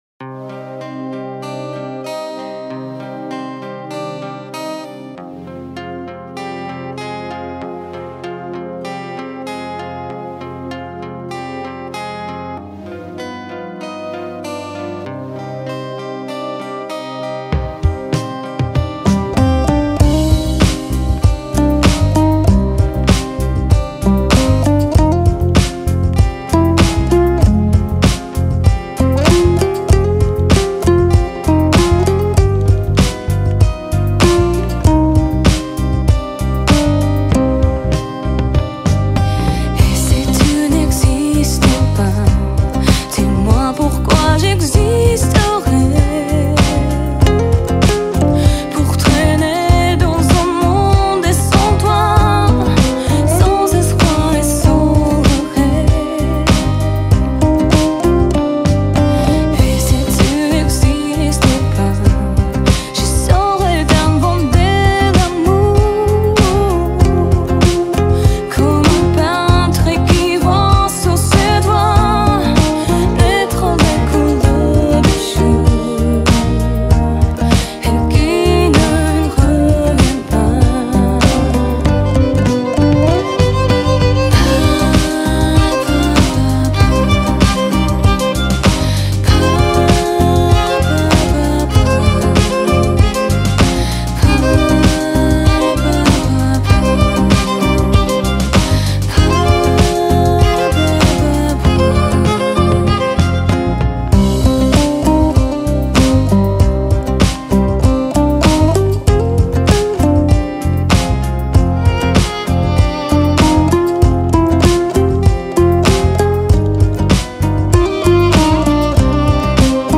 dombyra cover